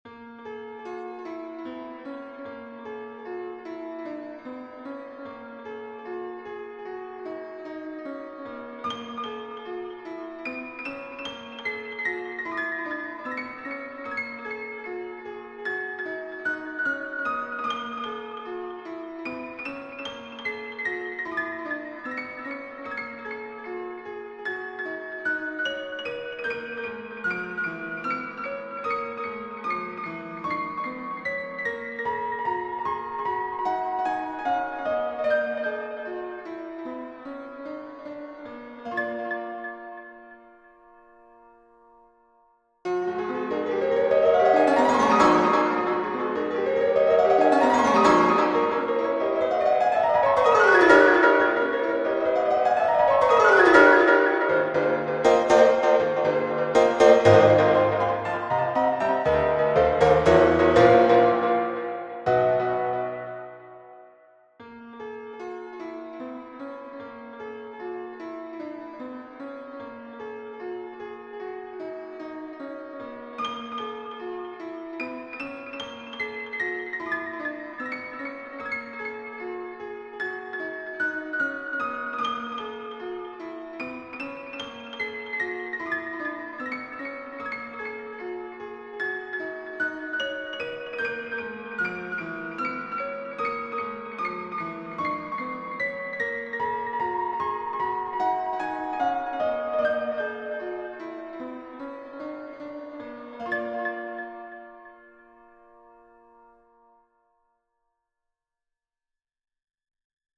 Microtonal Miniature in Bb - Piano Music, Solo Keyboard - Young Composers Music Forum
Microtonal Miniature in Bb